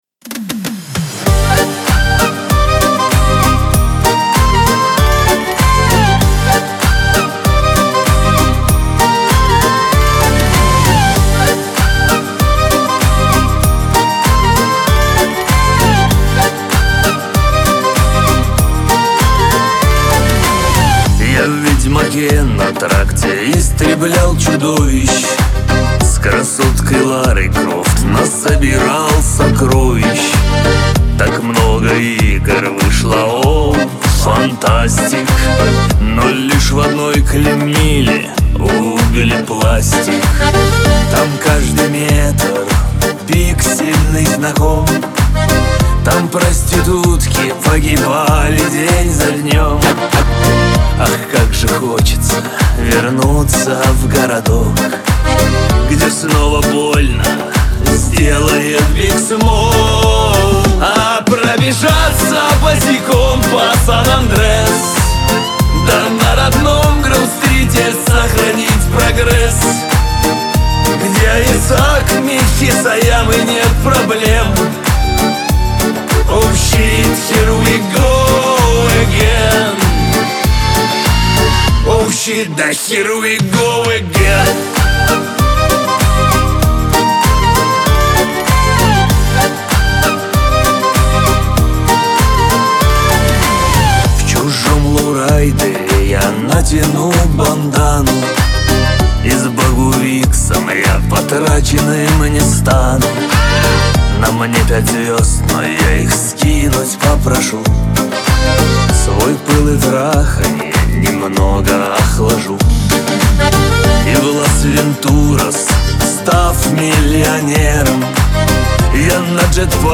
диско
Шансон